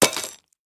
UI_BronzeFall_Soil_01.ogg